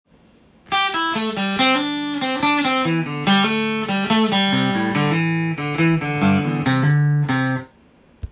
エレキギターで弾ける【歌うAマイナーペンタトニックスケール】シーケンスパターン【オリジナルtab譜】つくってみました
【歌うAマイナーペンタトニックスケール】シーケンスパターン